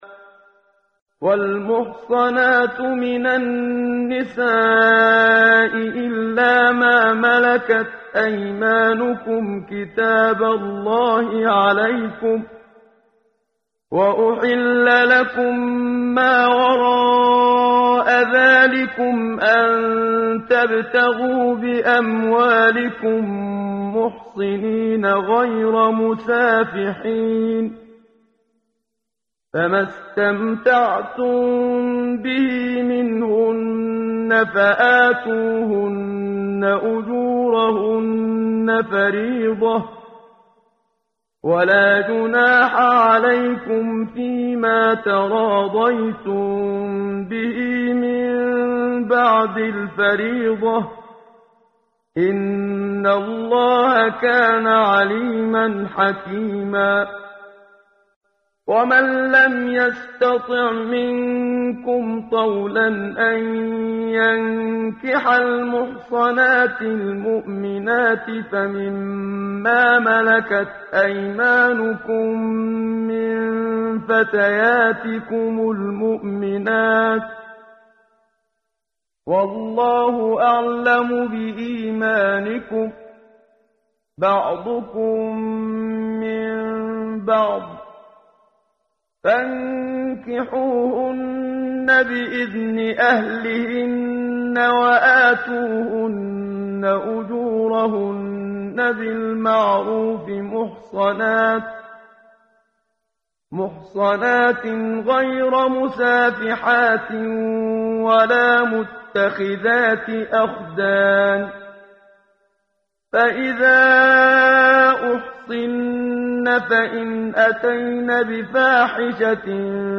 ترتیل صفحه 82 سوره مبارکه سوره نساء (جزء پنجم) از سری مجموعه صفحه ای از نور با صدای استاد محمد صدیق منشاوی
quran-menshavi-p082.mp3